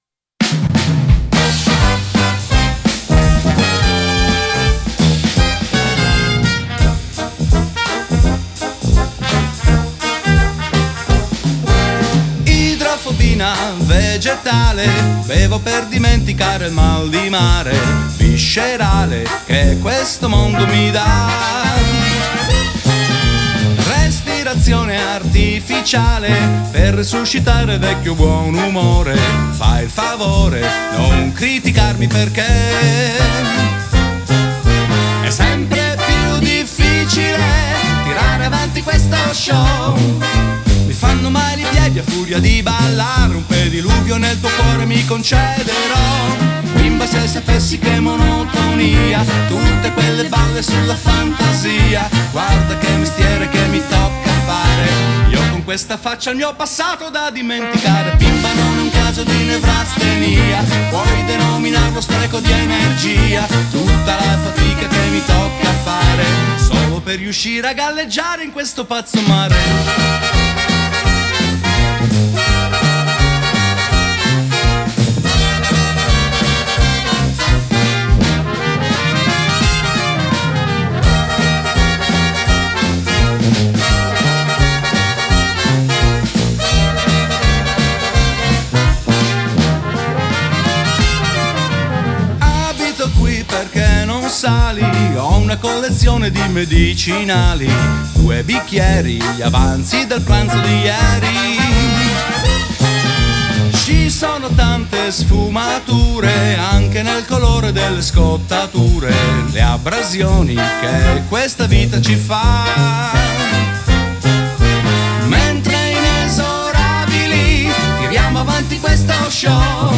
Genre: Swing, Jazz